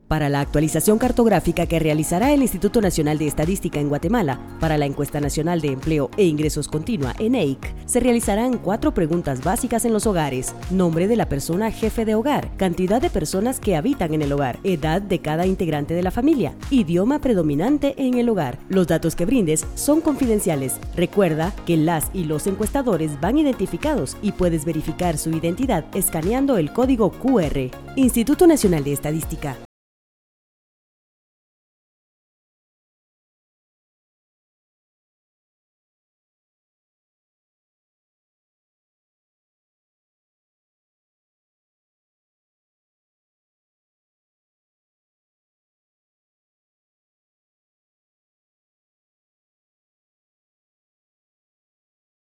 Spot de radio #2